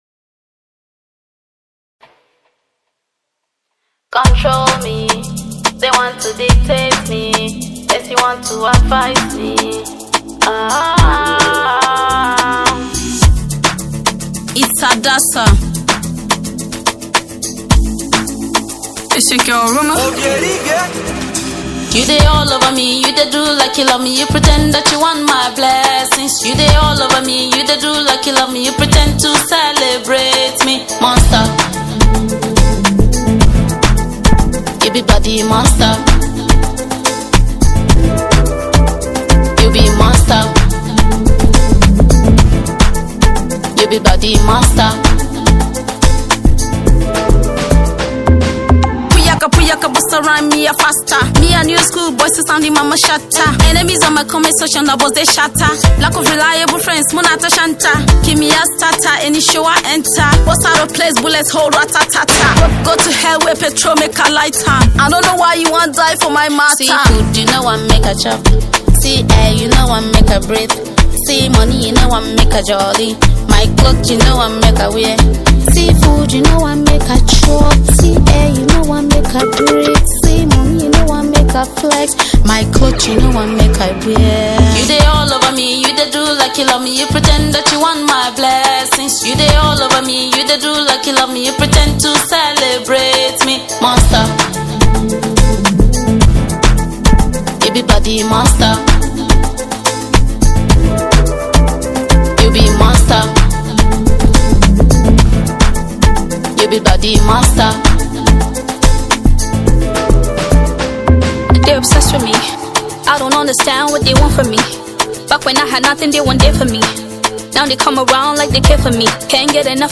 Hausa Hip Hop